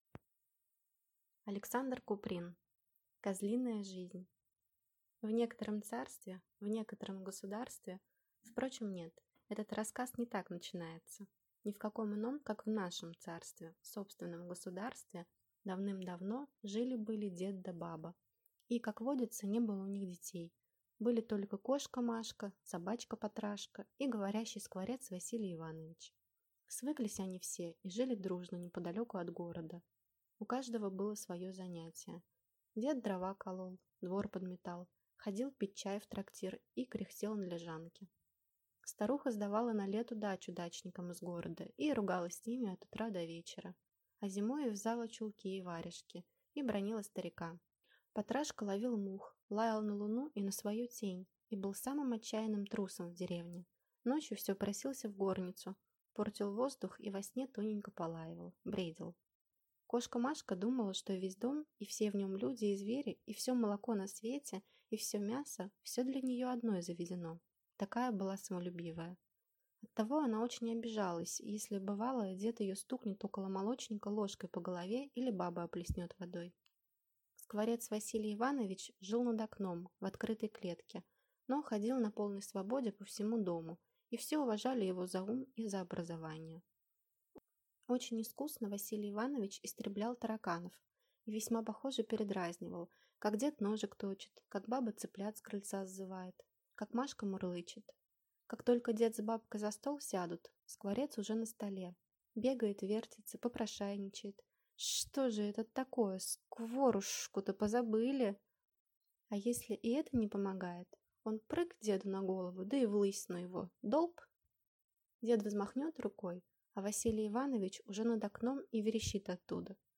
Аудиокнига Козлиная жизнь